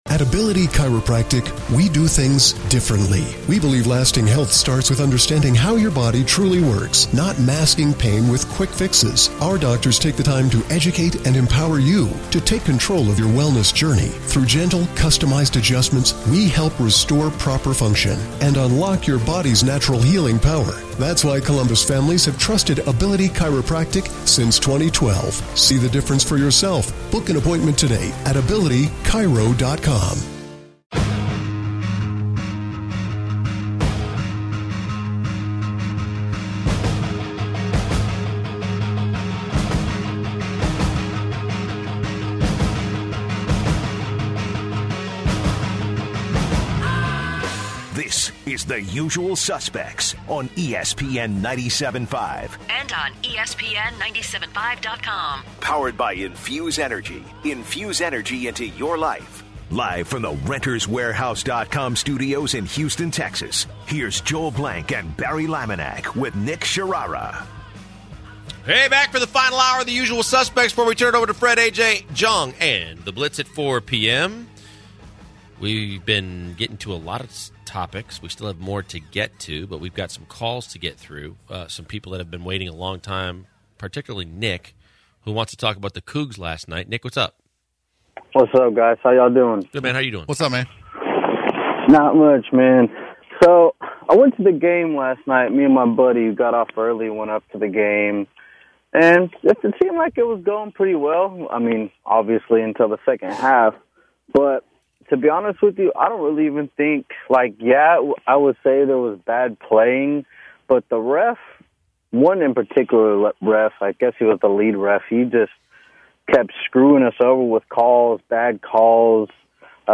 In the final hour the guys answer some questions from callers. The guys also give their picks for the NFL and NCAA this weekend.